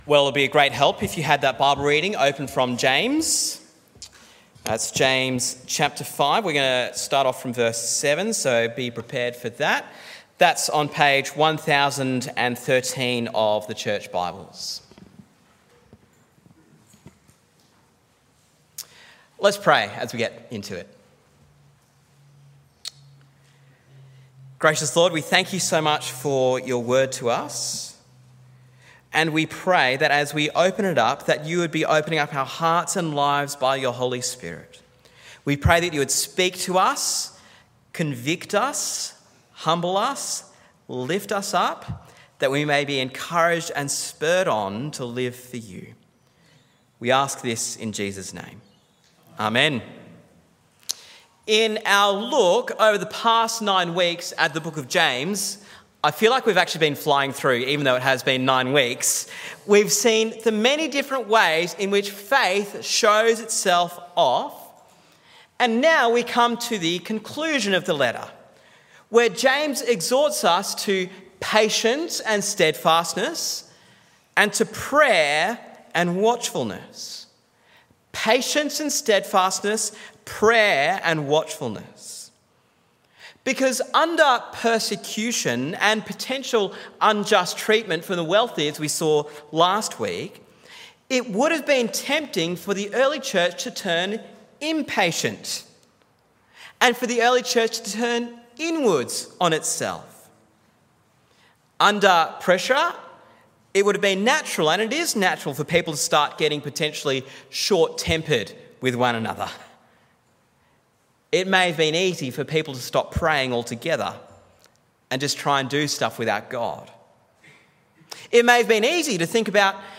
Listen to the sermon on James 5:9-20 in our Faith in Action series.